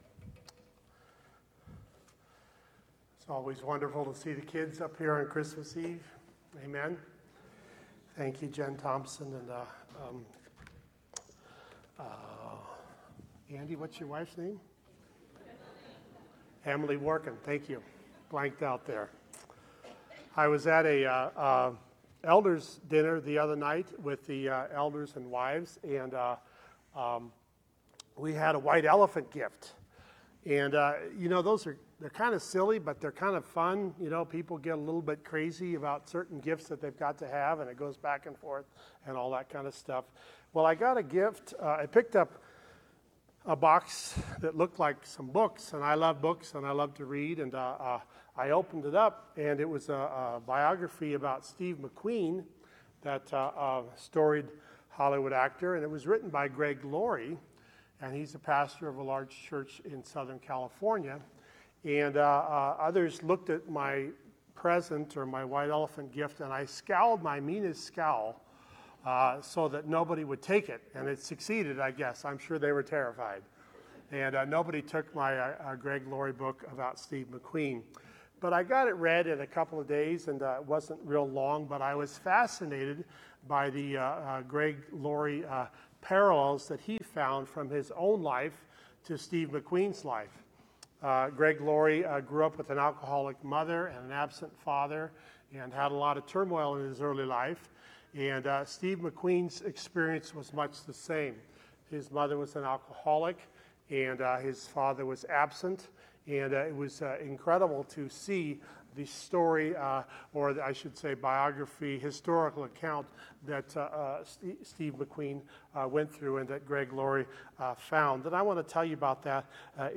Christmas Eve Candlelight Service Dec 14, 2021